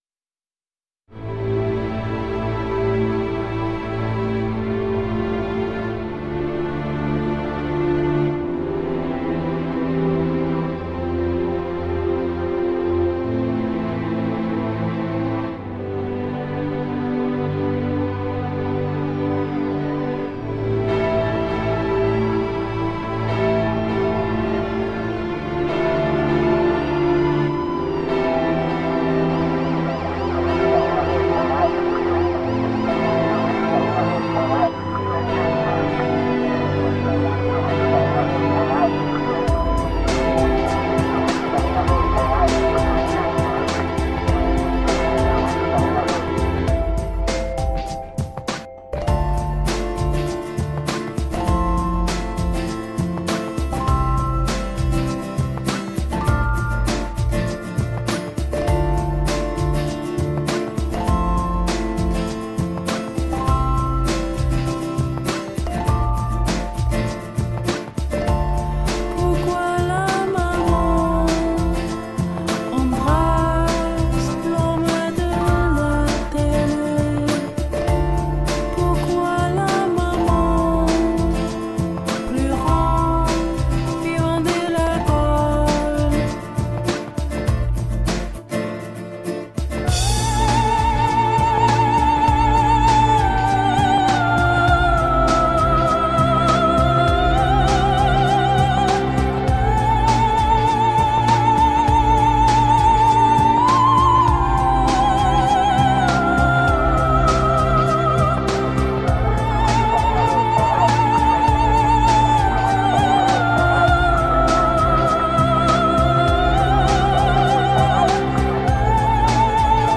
Puccini meets sigur rós.